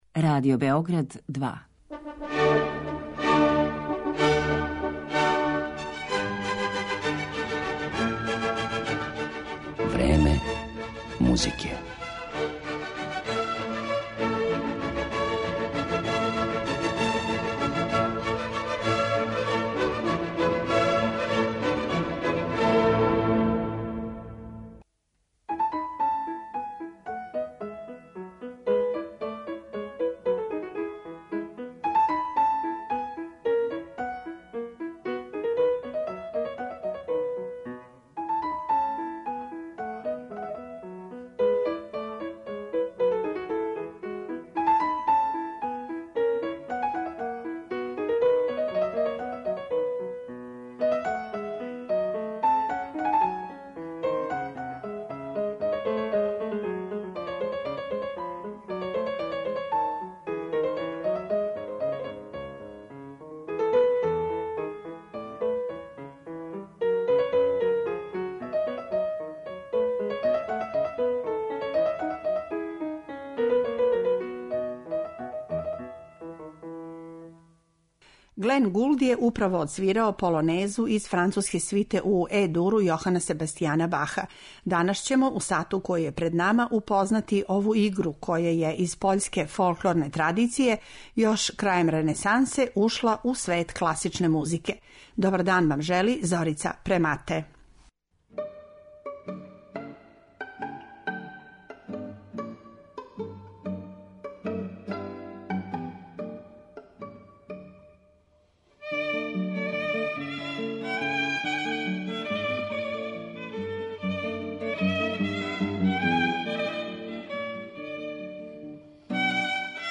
Емитоваћемо полонезе из пера Баха, Паганинија, Бетовена, Глинке, Дворжака, Чајковског и Шопена.